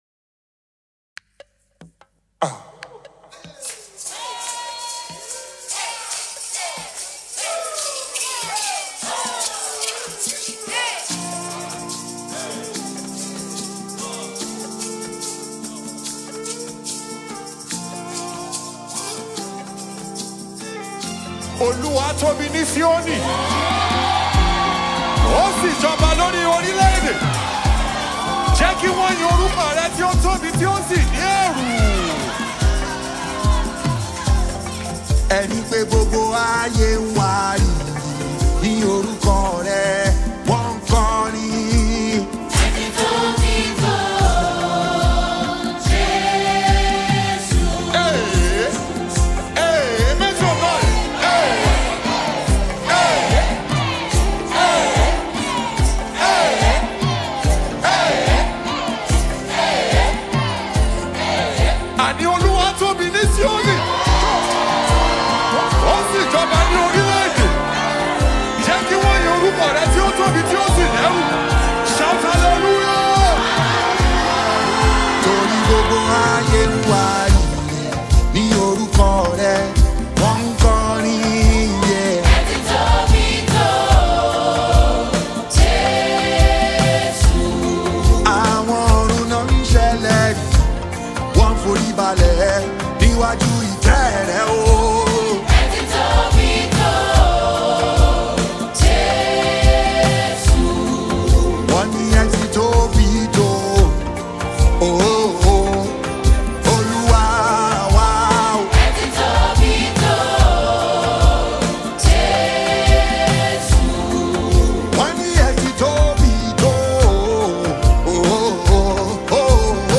Christian/Gospel
be it Afro-Gospel or contemporary tune